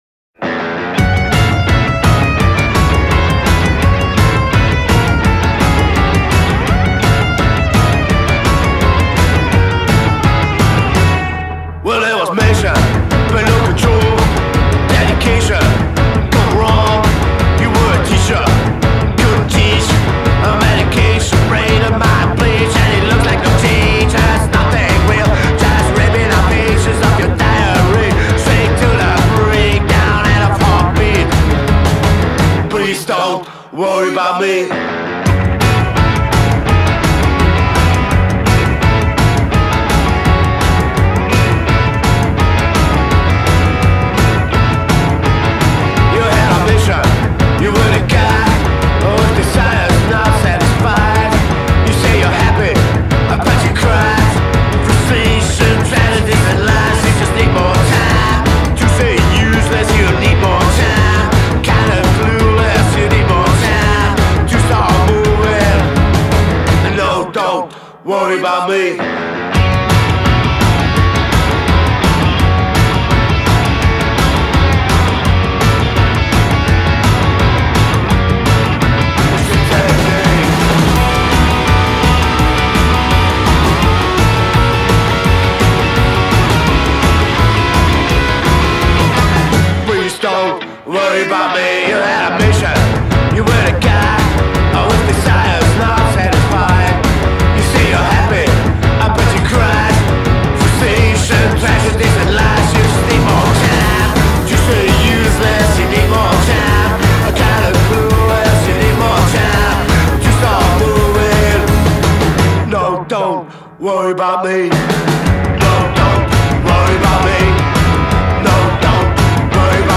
Punkabilly